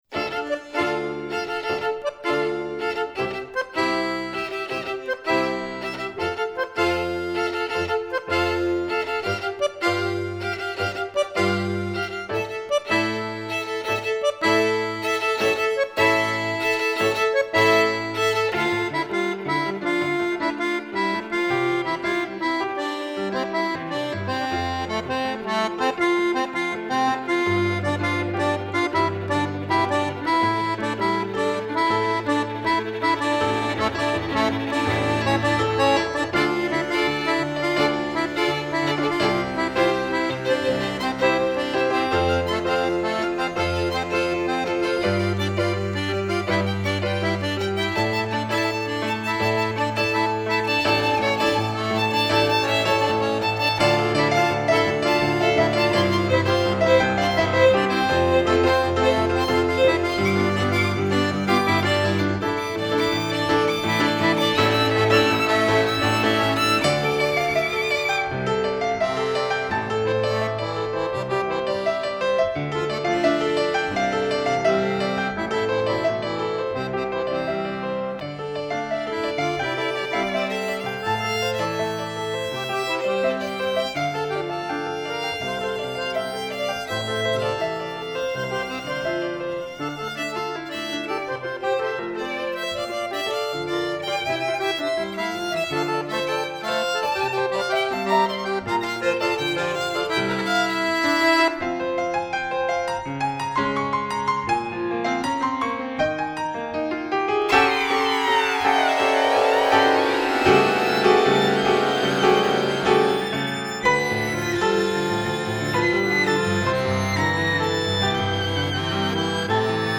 Fisarmonica, Violino e Pianoforte